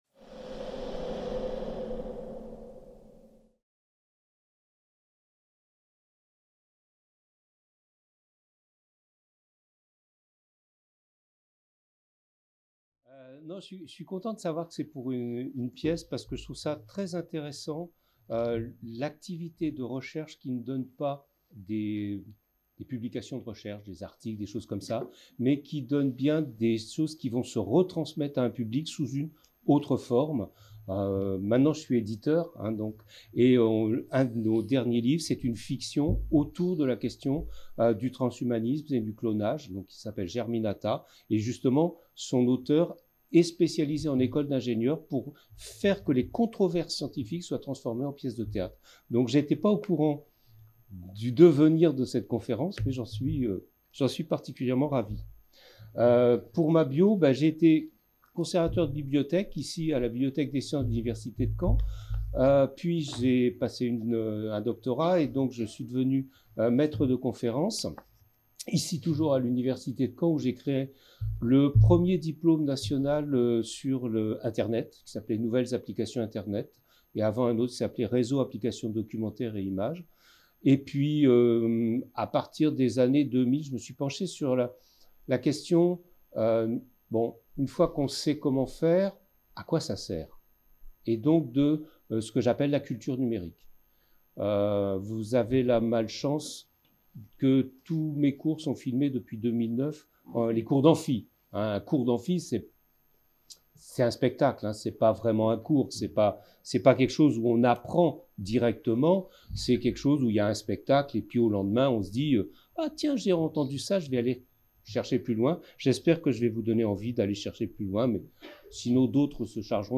Séminaire des invités Master Sociologie